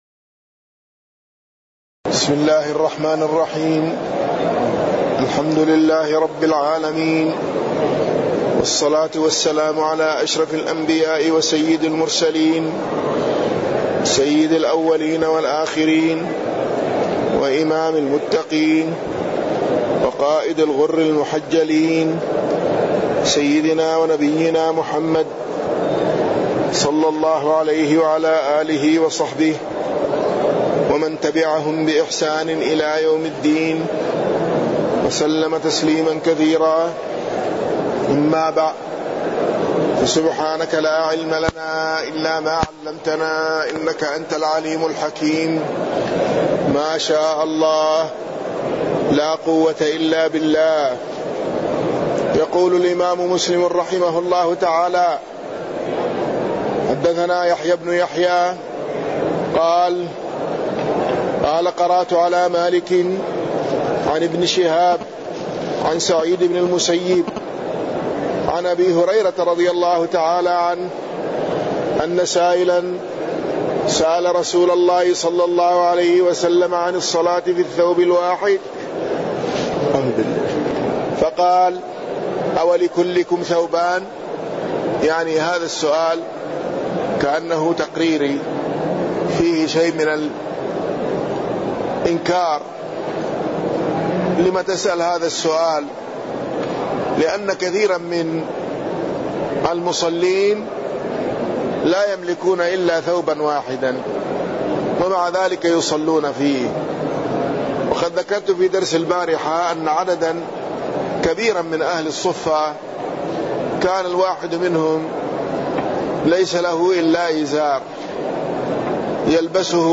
تاريخ النشر ٨ ربيع الثاني ١٤٢٩ هـ المكان: المسجد النبوي الشيخ